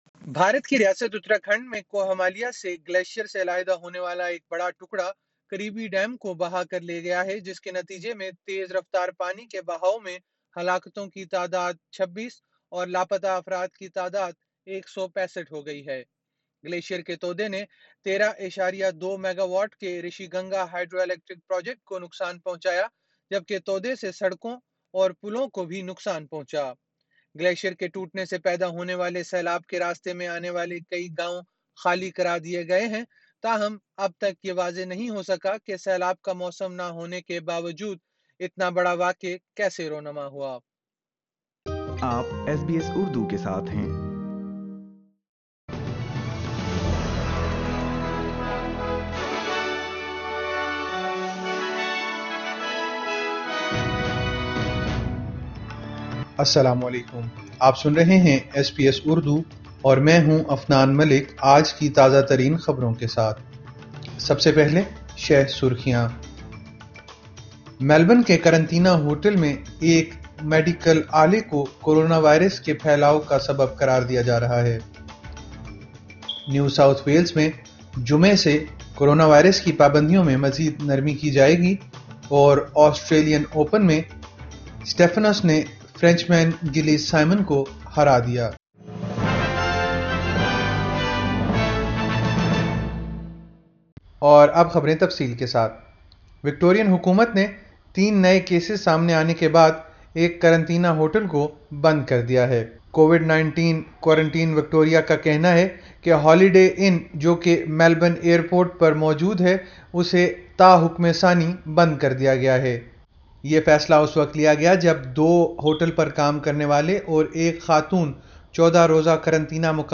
ایس بی ایس اردو خبریں 10 فروری 2021